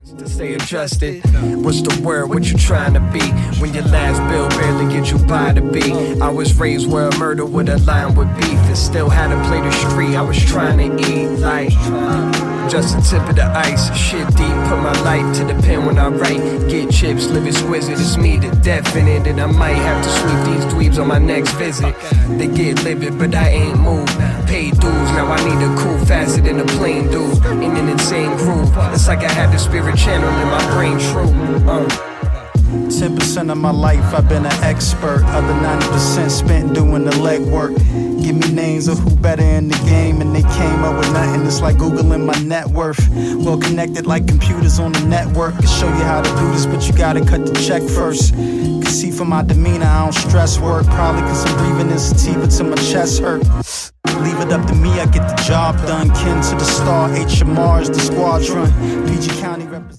Real nice jazzy rap action